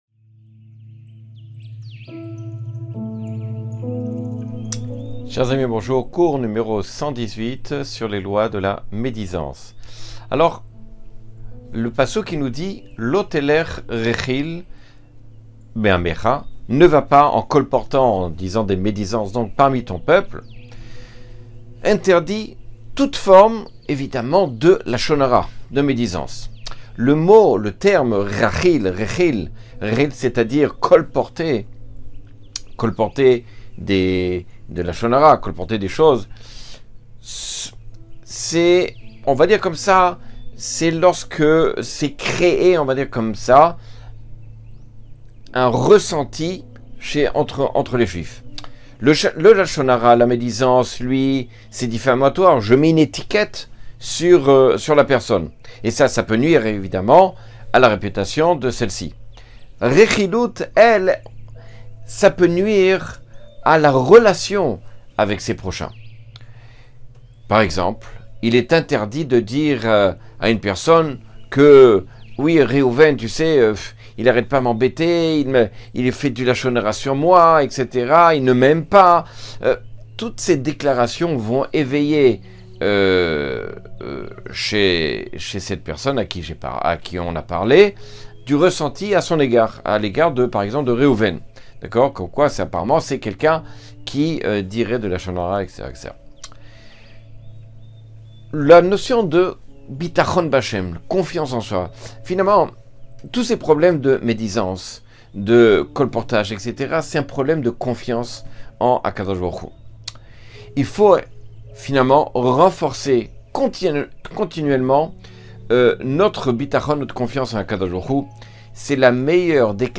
Cours 118 sur les lois du lashon hara.